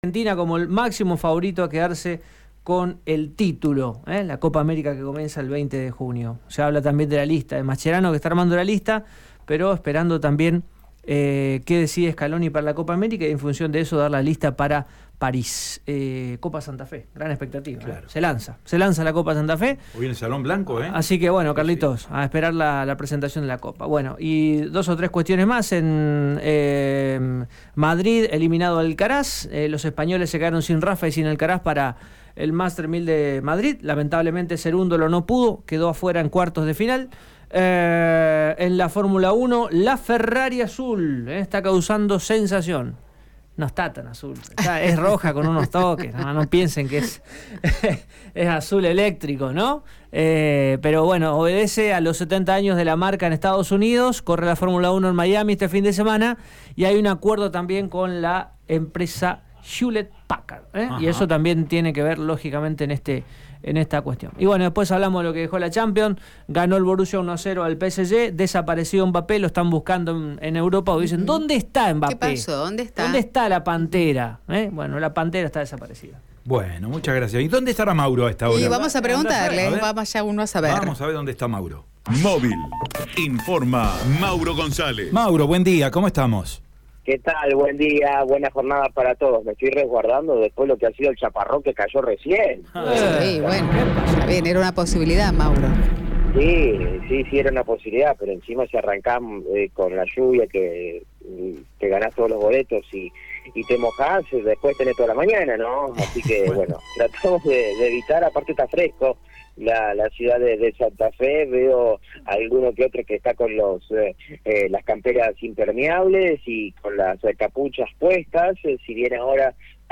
Policiales
Sin embargo, al borde la medianoche, el joven buscado se presentó en sede policial, acompañado de un abogado, y se puso a disposición de la Justicia, reportó el móvil de Radio EME.